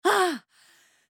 Звуки напряжения от тяжести
звук женского усилия